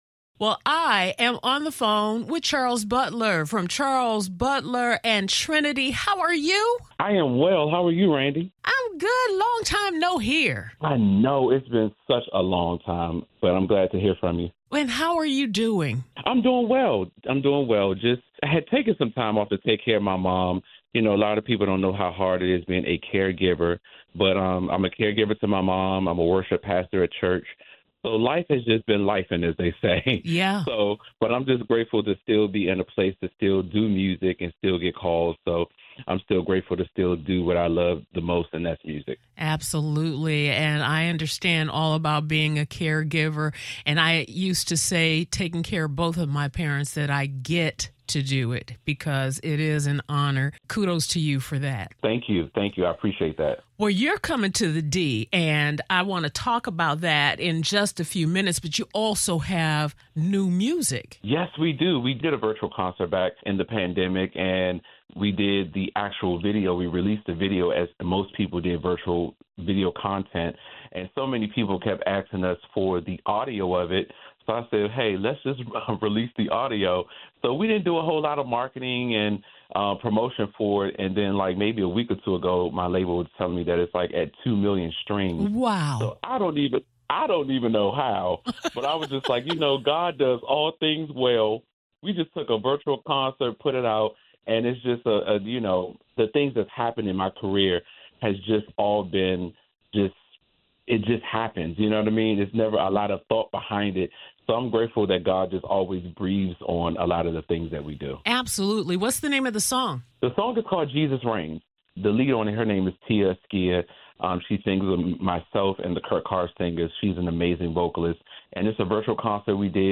Interview Below